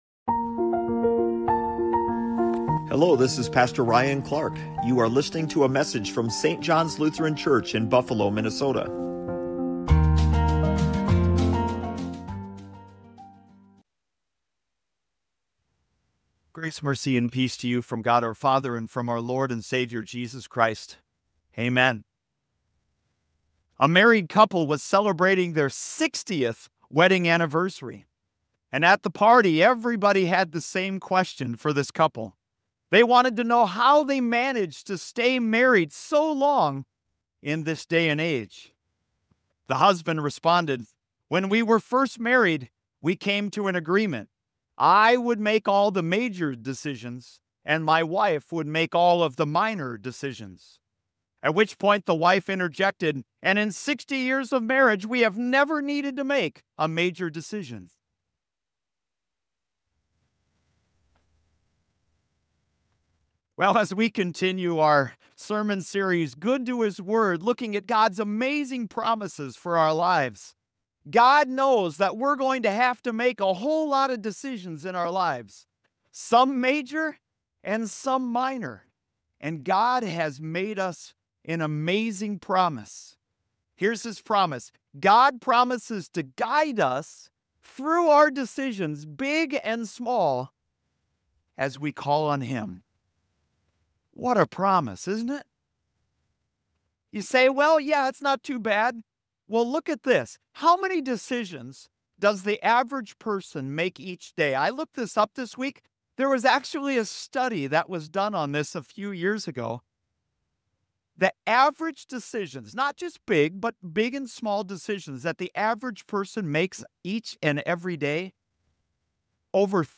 📅 We have an unwavering promise from the Lord to lean on that He will guide us. Allow your faith to be strengthened as you hear this amazing promise of God for you and learn how to call on Him in every decision in the 8th message in our sermon series, Good to His Word.